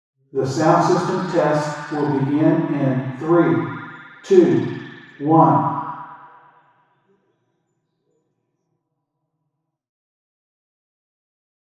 Extreme Flutter Echo taken at a pistol shooting range.